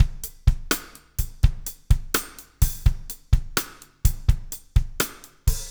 BLUE STICK-R.wav